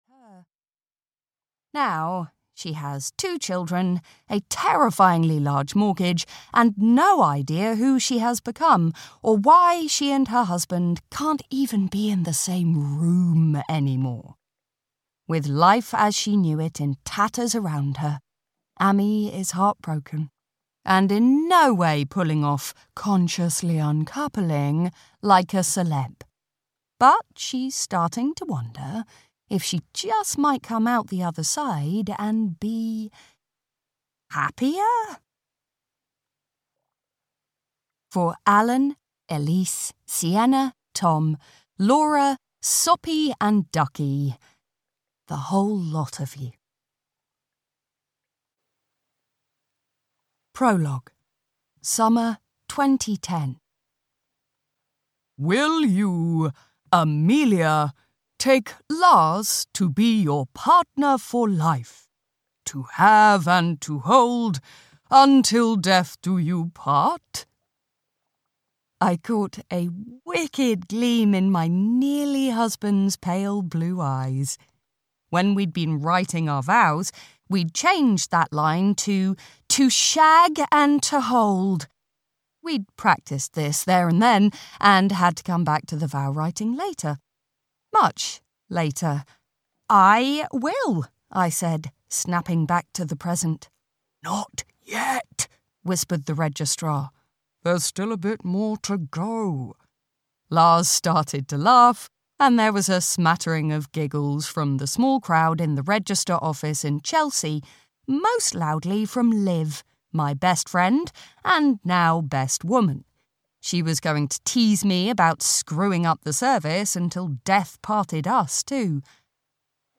How Not to Get Divorced (EN) audiokniha
Ukázka z knihy